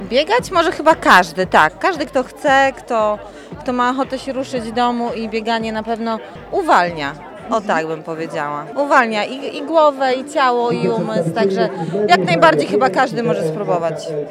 trener personalny.